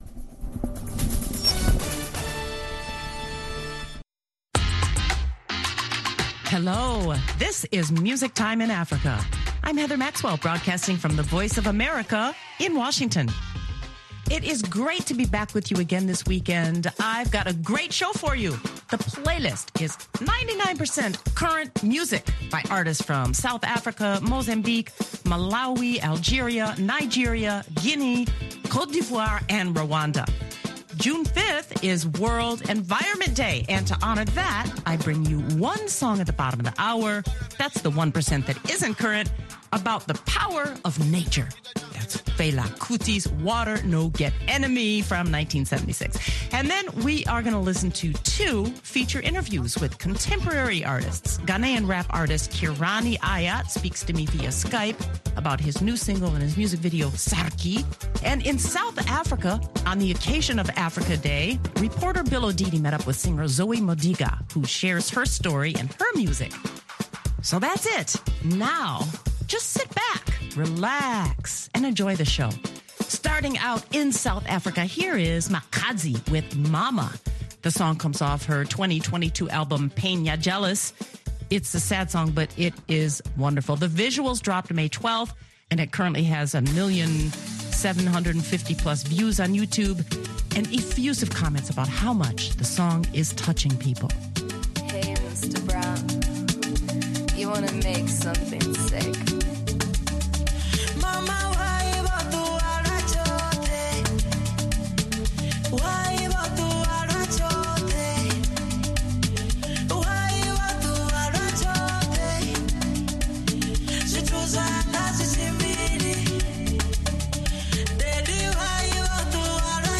Inside this edition, two interviews feature contemporary artists.